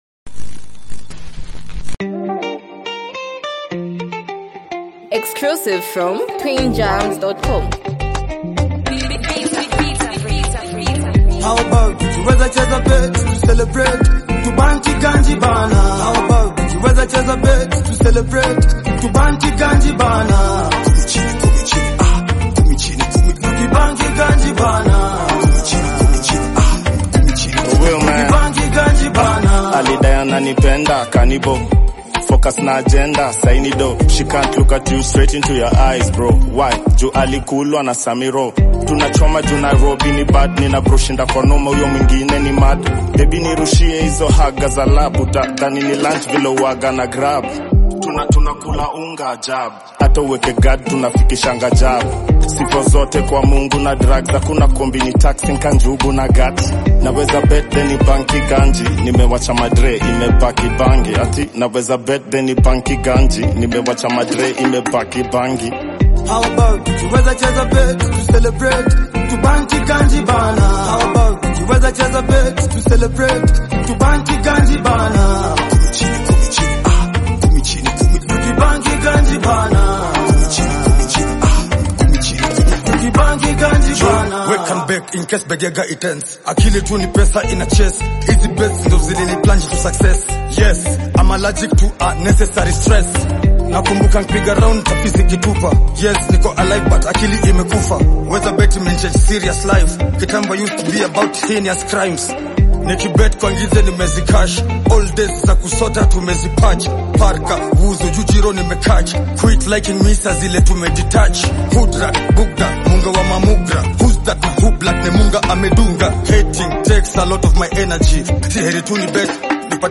Known for their raw lyricism and Sheng-infused rap style